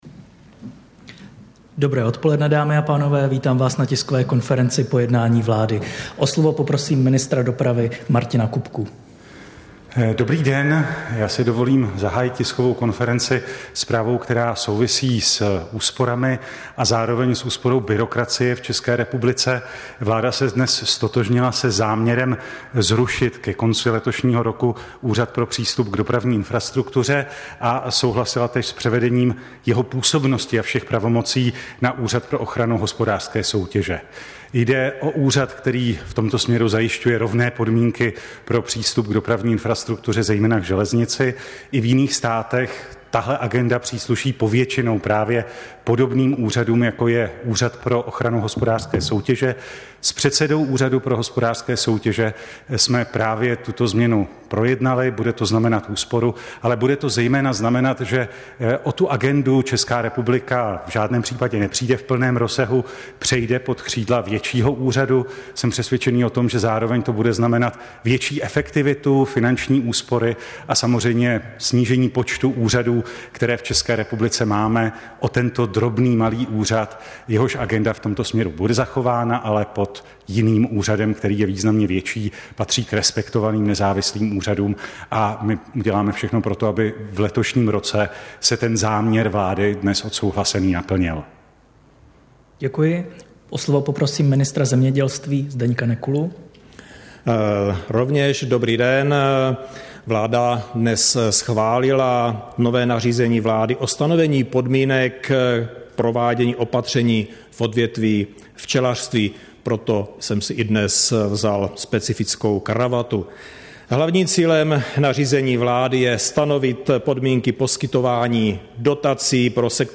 Tisková konference po jednání vlády, 22. února 2023